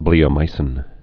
(blēə-mīsĭn)